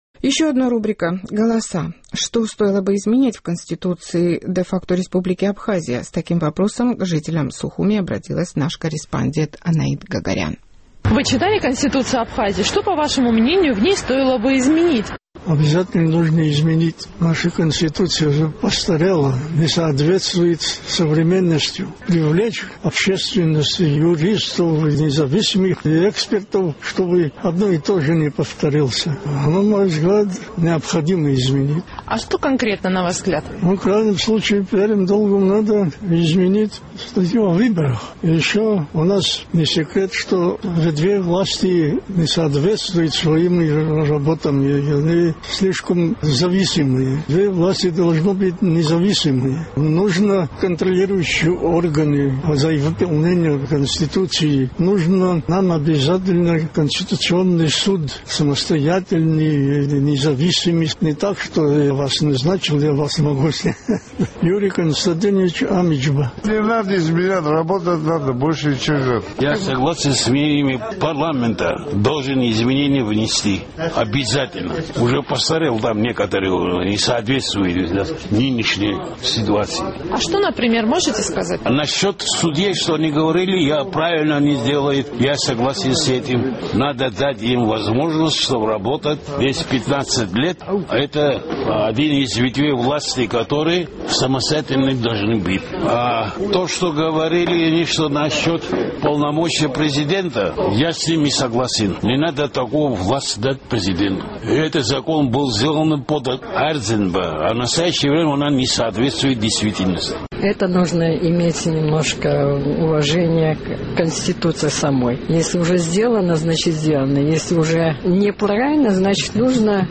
Наш сухумский корреспондент интересовался у жителей абхазской столицы, какие изменения, по их мнению, стоило бы внести в Конституцию республики.